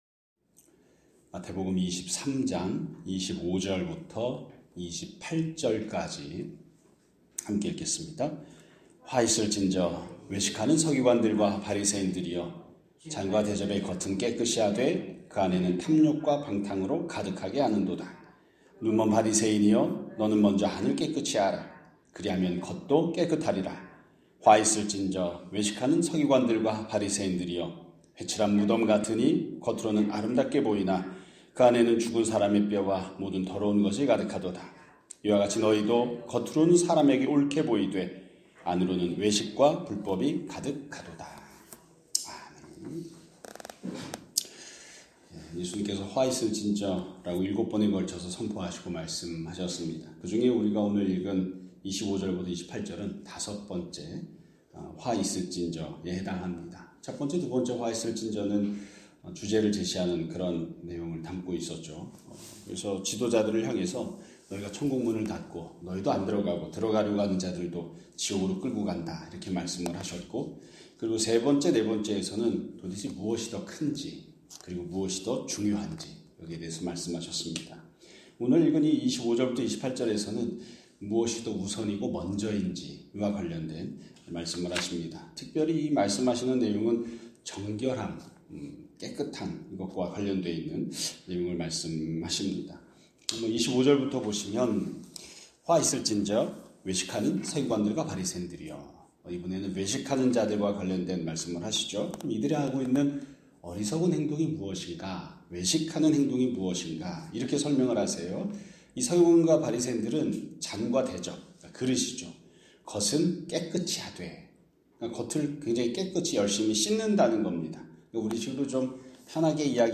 2026년 2월 26일 (목요일) <아침예배> 설교입니다.